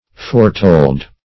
Foretold \Fore*told"\,